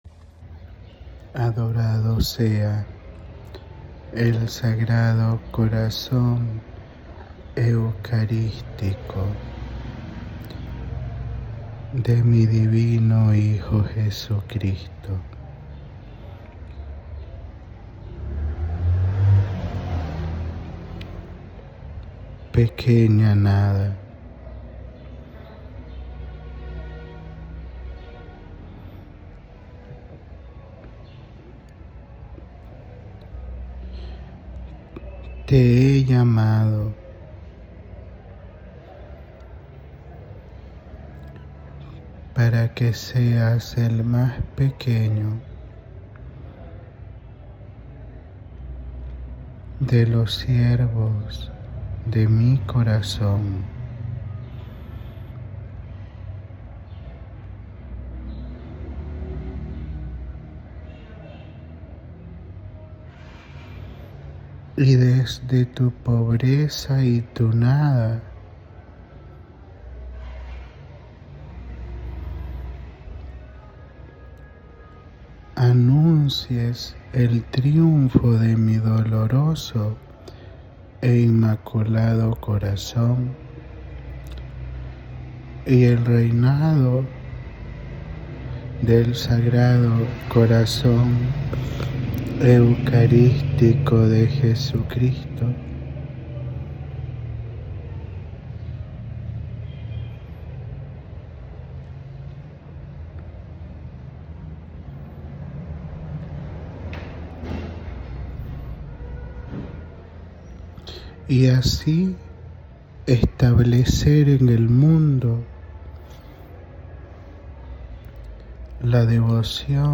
Audio da Mensagem